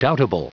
Prononciation audio / Fichier audio de DOUBTABLE en anglais
Prononciation du mot doubtable en anglais (fichier audio)